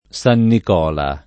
San nik0la] top.